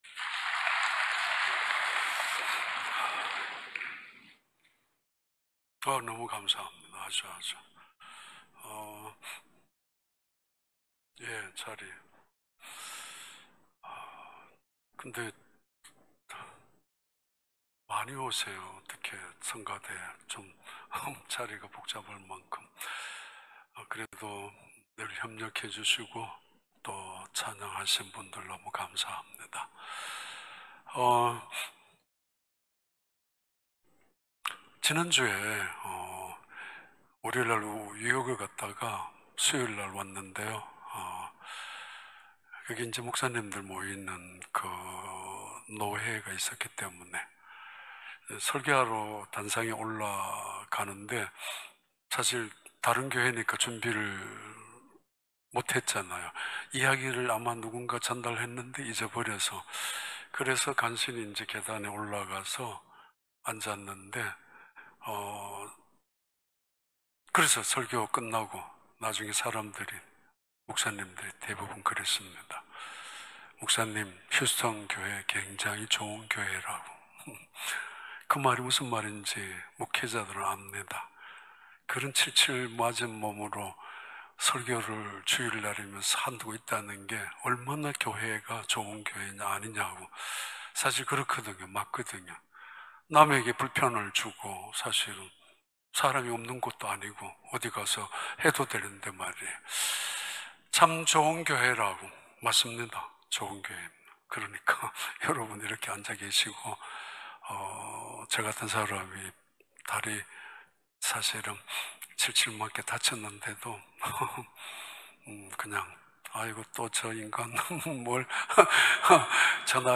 2021년 7월 25일 주일 4부 예배